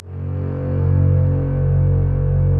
Index of /90_sSampleCDs/Optical Media International - Sonic Images Library/SI1_Swell String/SI1_Mello Swell